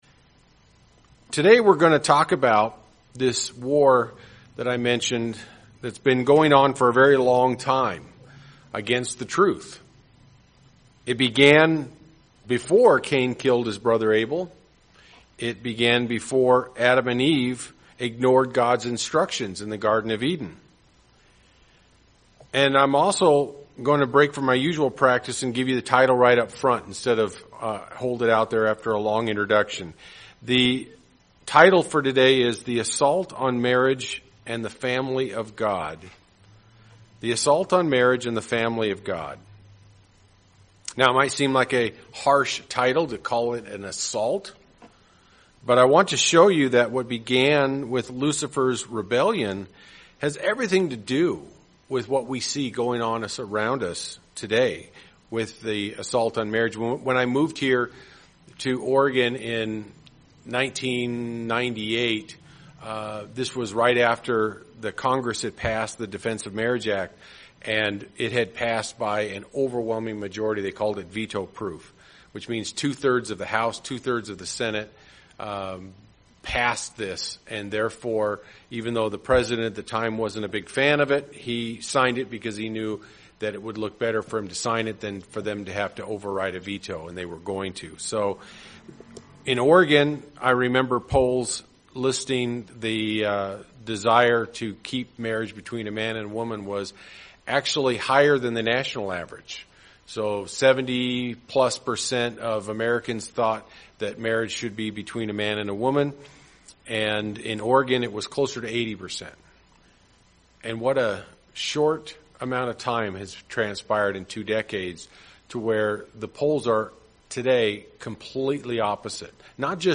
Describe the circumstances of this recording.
This sermon was given at the Bend-Redmond, Oregon 2019 Feast site.